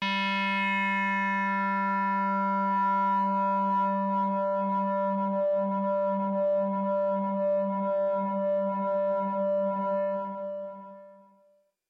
描述：通过Modular Sample从模拟合成器采样的单音。
标签： MIDI-速度-63 FSharp4 MIDI音符-67 挡泥板-Chroma-北极星 合成器 S英格尔音符 多重采样
声道立体声